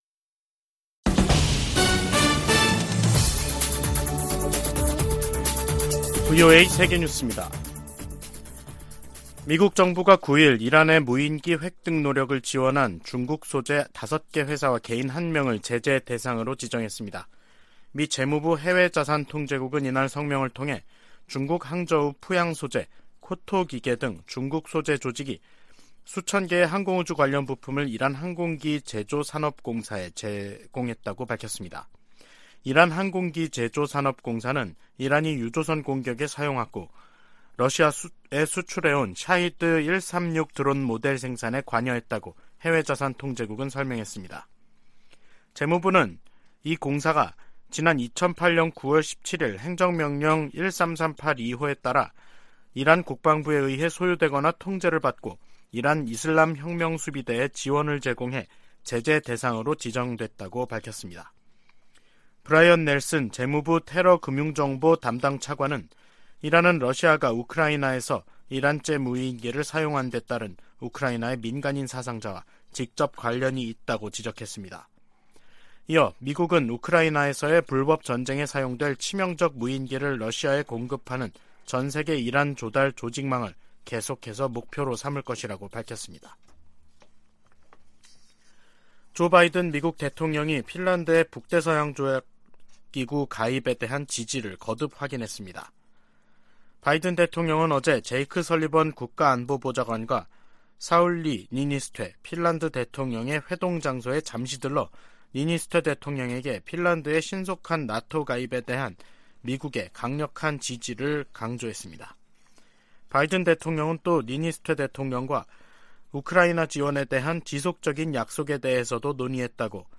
VOA 한국어 간판 뉴스 프로그램 '뉴스 투데이', 2023년 3월 10일 3부 방송입니다. 북한이 9일 신형 전술유도무기로 추정되는 탄도미사일을 최소 6발 서해로 발사했습니다. 미 국무부는 북한의 미사일 발사를 규탄하며, 대화에 열려 있지만 접근법을 바꾸지 않을 경우 더 큰 대가를 치르게 될 것이라고 경고했습니다. 미국 전략사령관이 의회 청문회에서 북한의 신형 대륙간탄도미사일로 안보 위협이 높아지고 있다고 말했습니다.